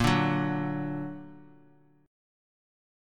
A#+ Chord
Listen to A#+ strummed